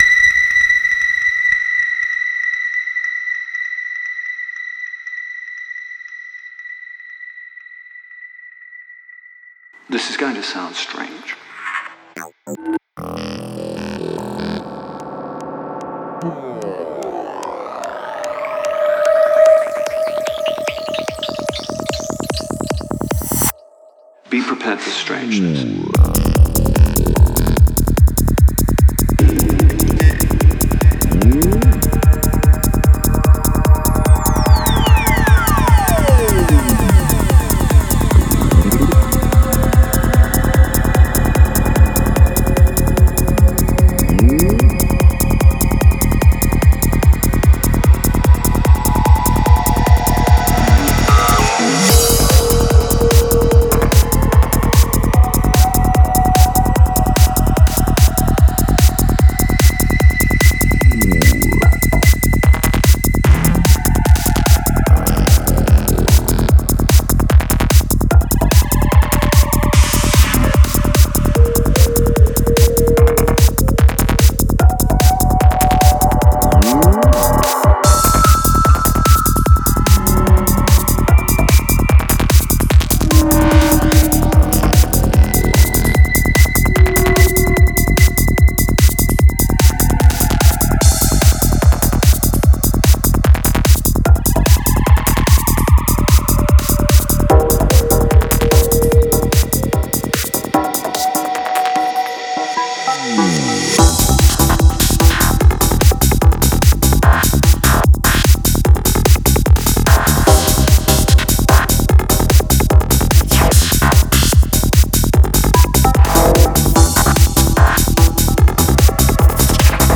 ژانر: سایکودلیک
توضیحات: موزیک سایکو دارک و بیس دار